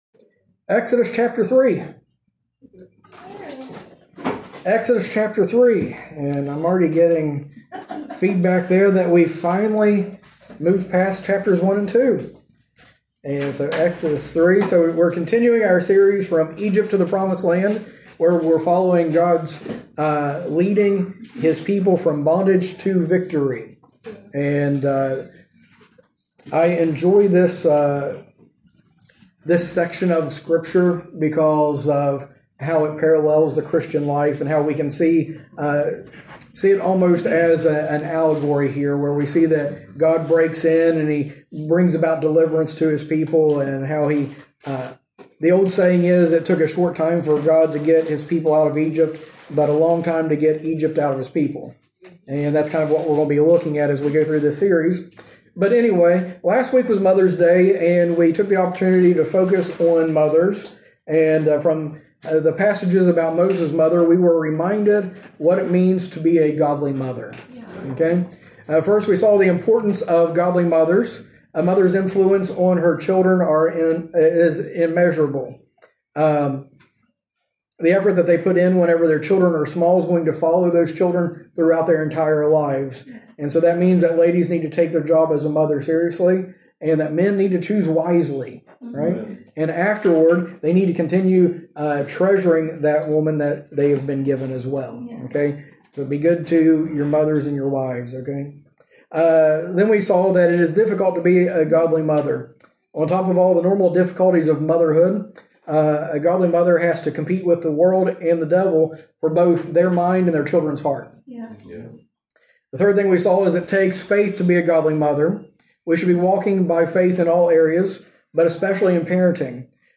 From Series: "Recent Sermons"